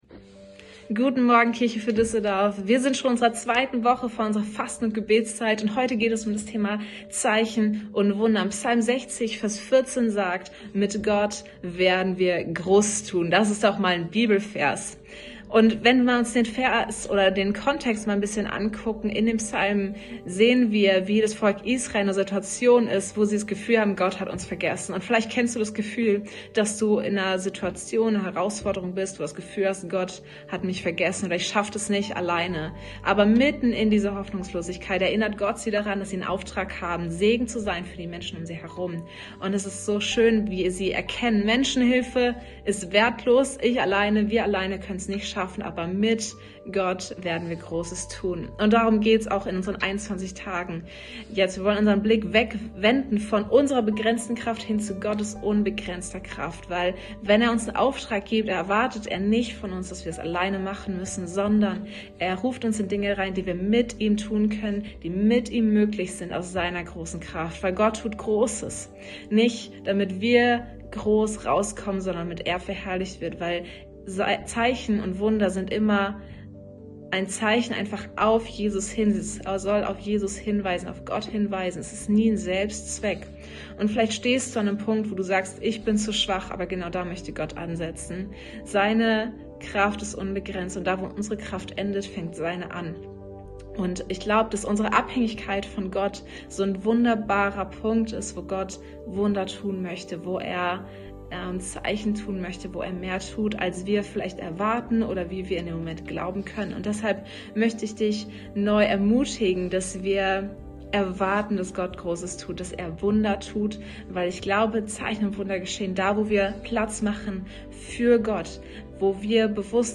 Tag 11 der Andacht zu unseren 21 Tagen Fasten & Gebet